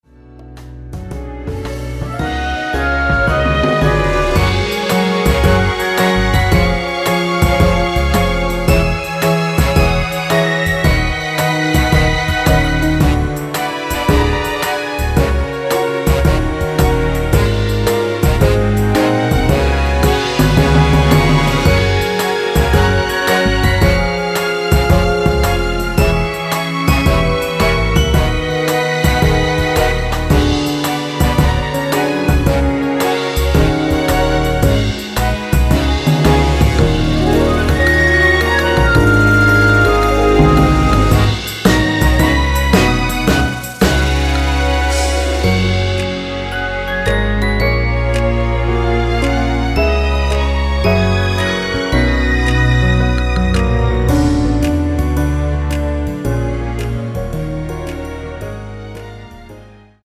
다음 간주가 길어서 4마디로 짧게 편곡 하였습니다.(미리듣기및 본문가사 확인)
원키에서(-8)내린 (1절+후렴)으로 진행되는 MR입니다.
Gb
앞부분30초, 뒷부분30초씩 편집해서 올려 드리고 있습니다.
중간에 음이 끈어지고 다시 나오는 이유는